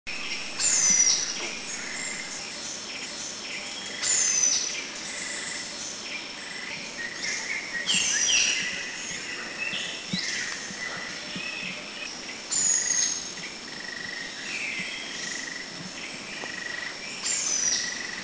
Scale-feathered Malkoha  Phaenicophaeus cumingi  Country endemic
B2A_Scale-featheredMalkohaMakiling210_SDW.mp3